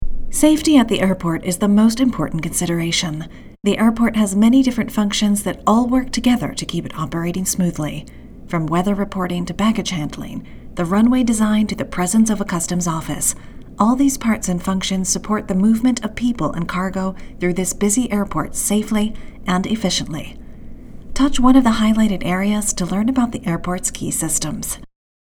Airport Safety US